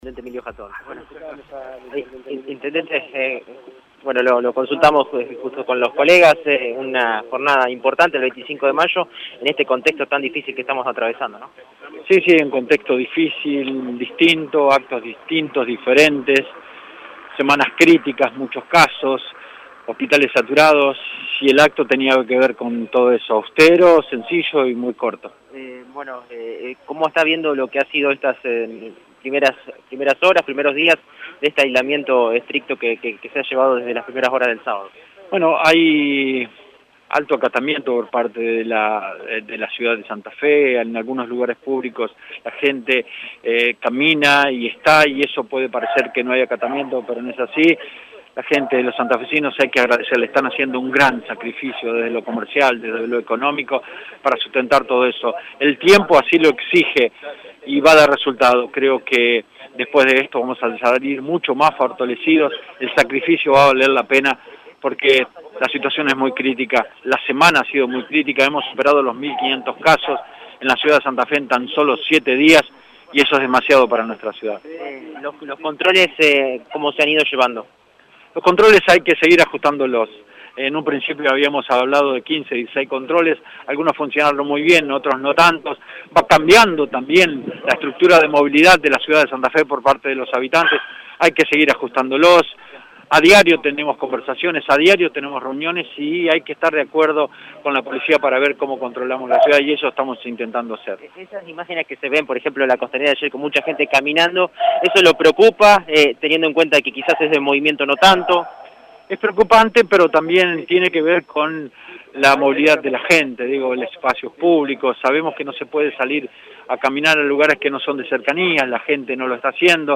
Así lo consideró el intendente de la ciudad de Santa Fe, Emilio Jatón, tras el acto en conmemoración del 211º aniversario de la Revolución de Mayo.
Tras el izamiento de la bandera en la plaza «25 de Mayo» de la capital provincial por el Día de la Patria, en dialogo con el móvil de Radio EME, Emilio Jatón evaluó el impacto de las restricciones en el territorio santafesino: «Hay alto acatamiento por parte de la ciudad de Santa Fe. Los santafesinos están haciendo un gran sacrificio en lo económico. El tiempo así lo exige y va a dar resultados. Después de esto vamos a volver más fortalecidos».